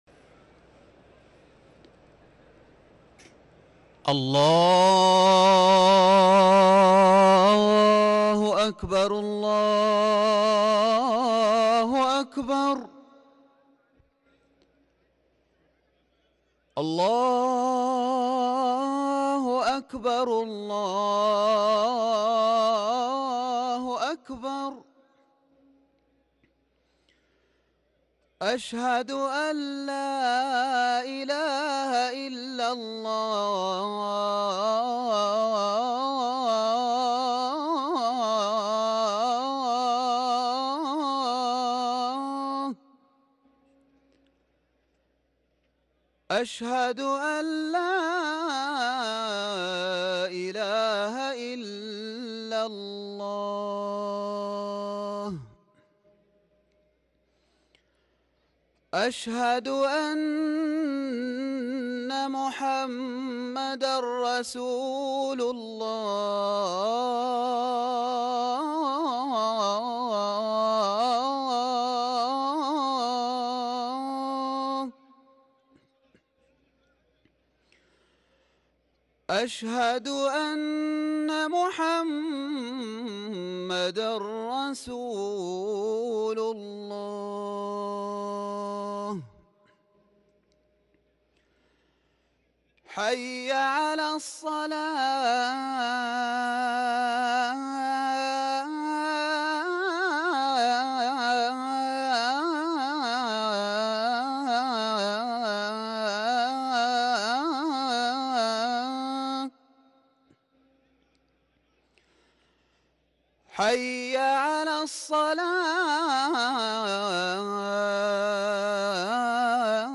اذان الجمعة الاول